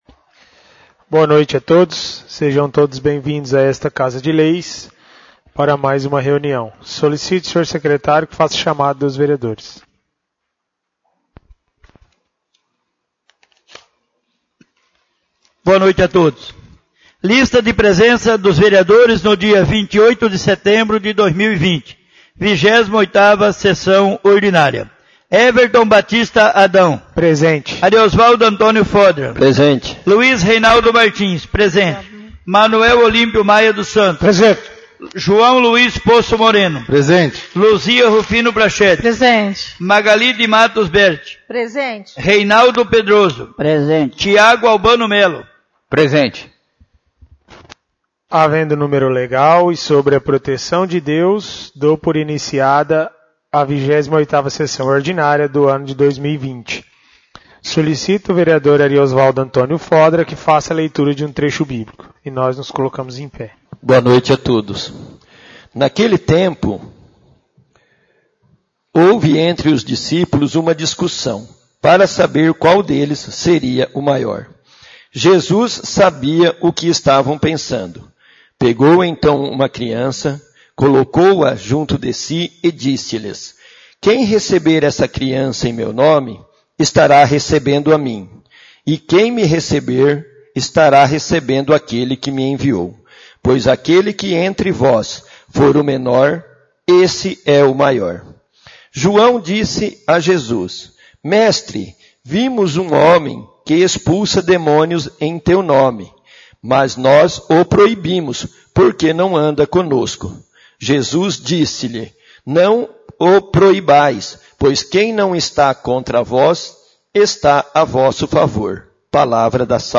28° SESSÃO ORDINÁRIA